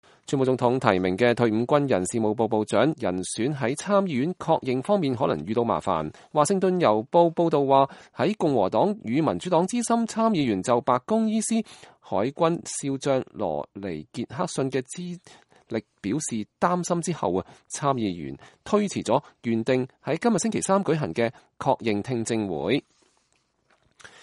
川普總統的醫生傑克遜在白宮介紹總統體檢後的健康狀況。（2018年1月16日）